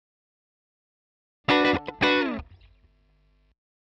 120_Guitar_funky_riff_E_4.wav